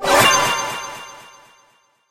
screen_wipe_01.ogg